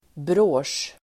Ladda ner uttalet
Uttal: [brå:sj]